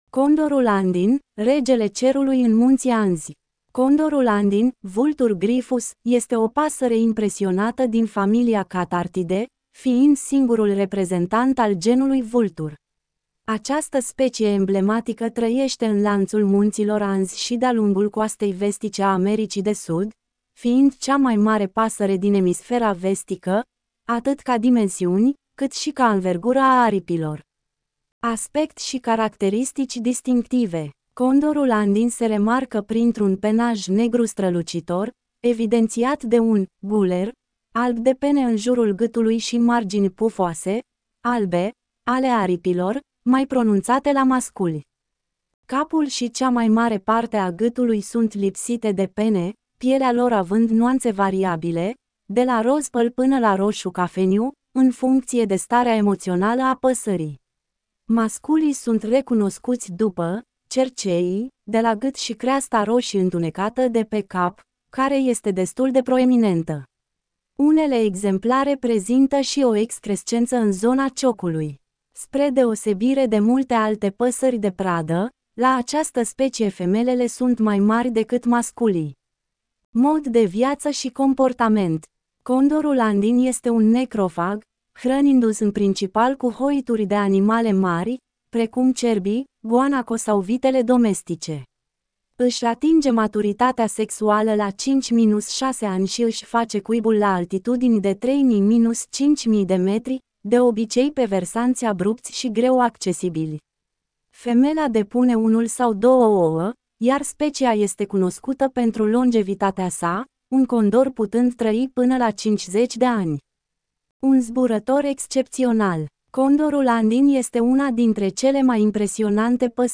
Get in touch with us Ascultă articolul Condorul andin (Vultur gryphus) este o pasăre impresionantă din familia Cathartidae, fiind singurul reprezentant al genului Vultur.